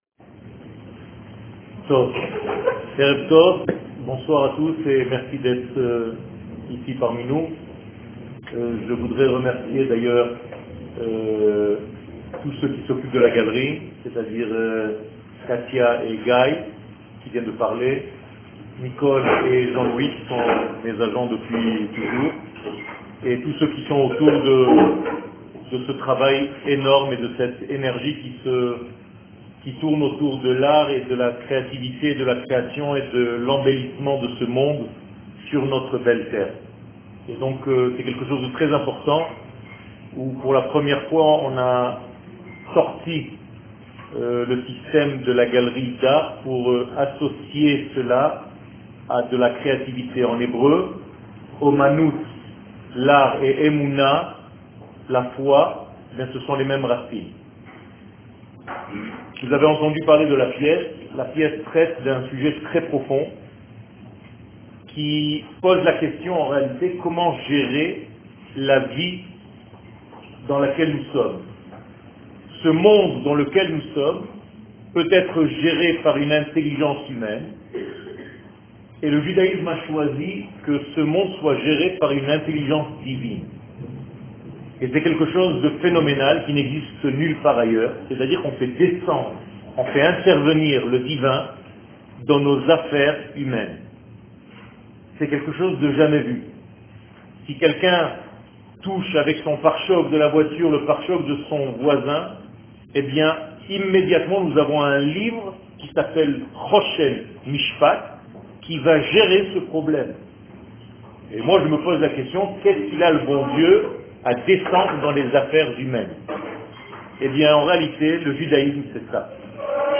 Identite שיעור מ 01 מאי 2018 35MIN הורדה בקובץ אודיו MP3 (16.31 Mo) הורדה בקובץ אודיו M4A (4.16 Mo) TAGS : Torah et identite d'Israel שיעורים קצרים